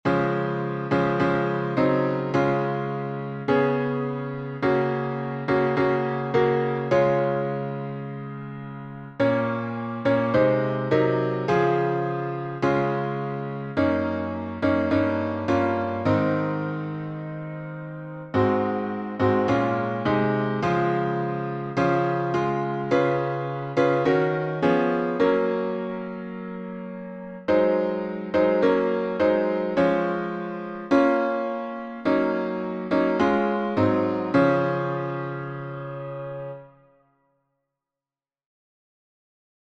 Words by William Walsham How Tune: RUTH (Smith) by Samuel Smith, 1865 Key signature: D flat major